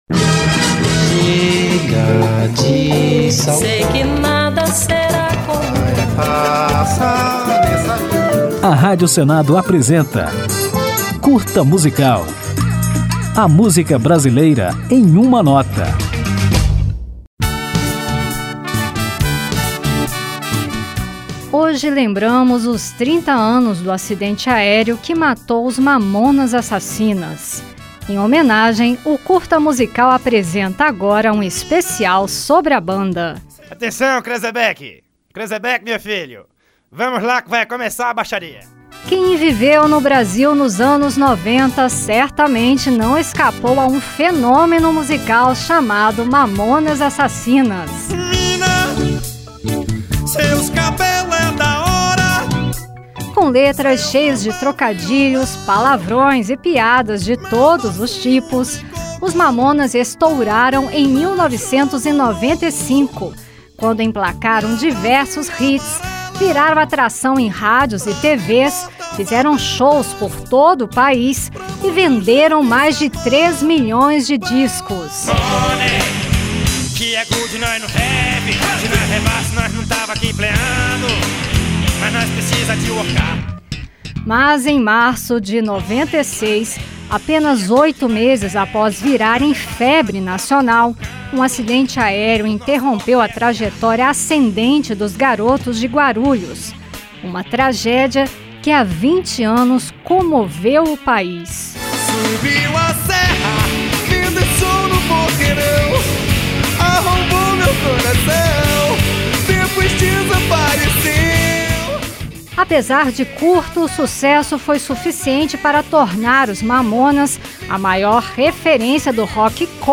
Nesta homenagem do Curta Musical, você vai relembrar a história deste grande fenômeno musical que arrebatou o público brasileiro nos anos 1990, com letras cheias de trocadilhos, palavrões e piadas de todos. Ao final, ouviremos Mamonas Assassinas na música Chopis Centis.